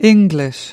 13. English  /ˈɪŋ.ɡlɪʃ/ : môn tiếng Anh
english.mp3